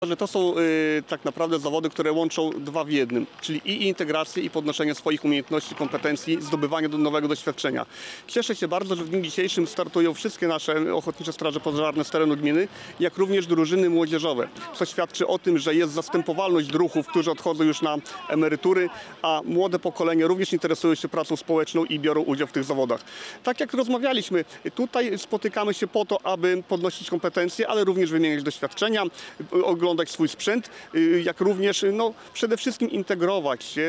Jak mówił Krzysztof Andrzej Gwaj, zastępca wójta gminy Suwałki, zawody podnoszą kompetencje, ale też integrują środowisko.